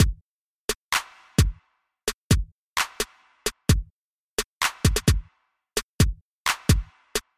描述：新鲜的，小鼓Kick节拍与飞拍。非常适合你的嘻哈节拍
标签： 130 bpm Hip Hop Loops Drum Loops 1.24 MB wav Key : Unknown
声道立体声